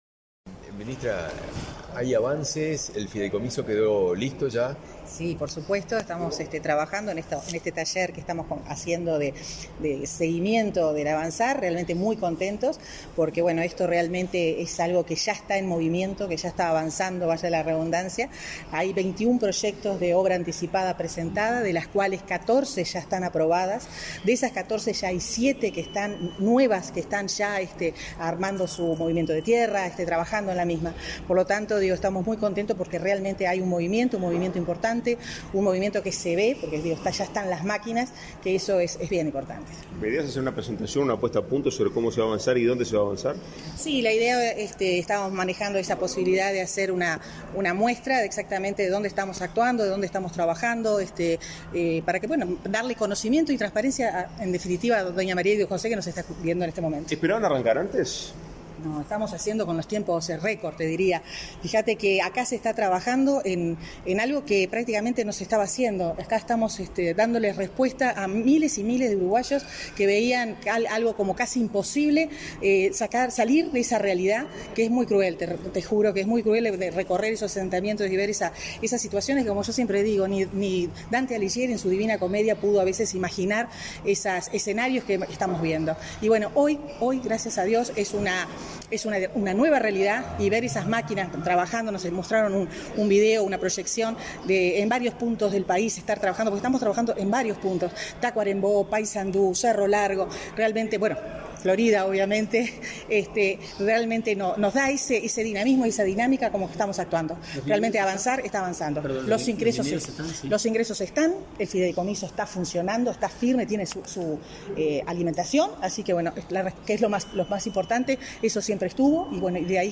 Declaraciones a la prensa de la ministra de Vivienda, Irene Moreira, y del presidente del Congreso de Intendentes, Guillermo López
El secretario de Presidencia, Álvaro Delgado, y el prosecretario, Rodrigo Ferrés, se reunieron con la ministra de Vivienda y Ordenamiento Territorial, Irene Moreira, y el presidente del Congreso de Intendentes, Guillermo López, este 30 de noviembre, para dar seguimiento al Plan Avanza. Tras el encuentro, Moreira y López realizaron declaraciones a la prensa.